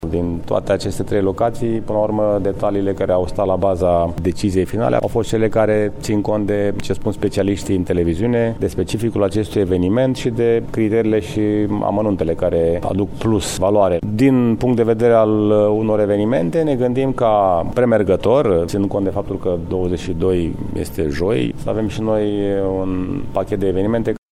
George Scripcaru, primar municipiul Brașov: